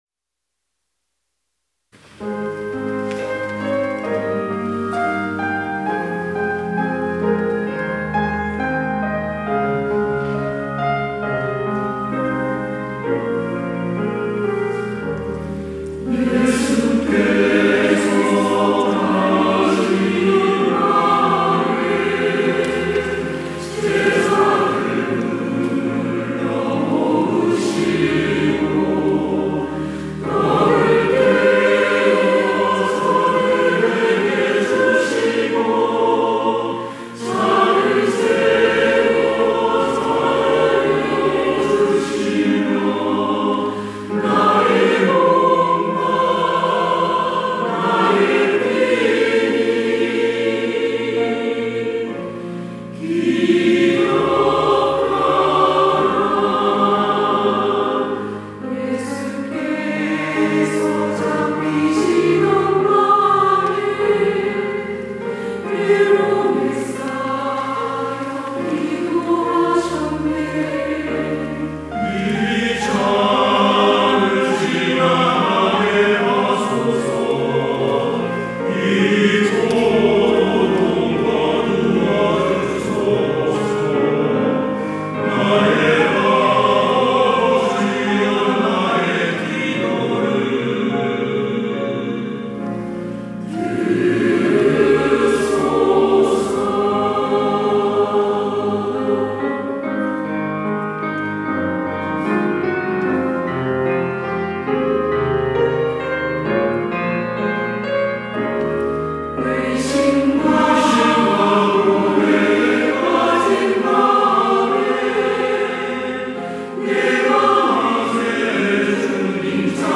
시온(주일1부) - 기억하라
찬양대